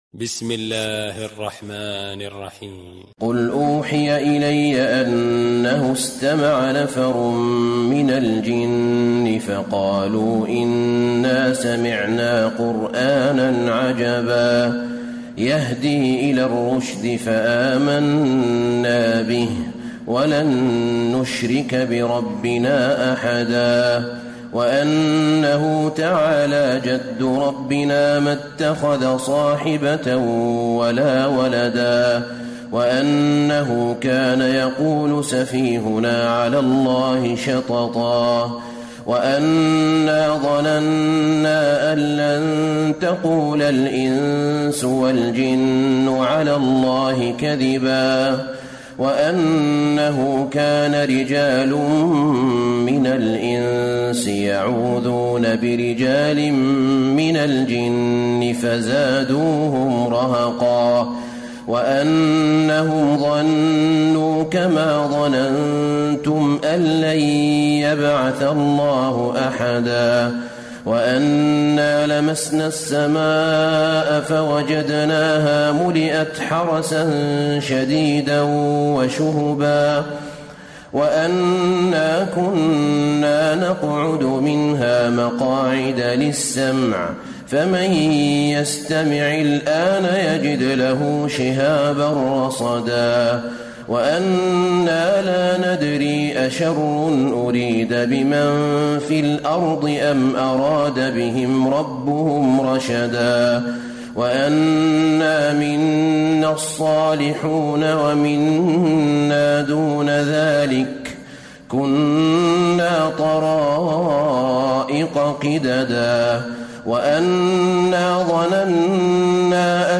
تراويح ليلة 28 رمضان 1434هـ من سورة الجن الى المرسلات Taraweeh 28 st night Ramadan 1434H from Surah Al-Jinn to Al-Mursalaat > تراويح الحرم النبوي عام 1434 🕌 > التراويح - تلاوات الحرمين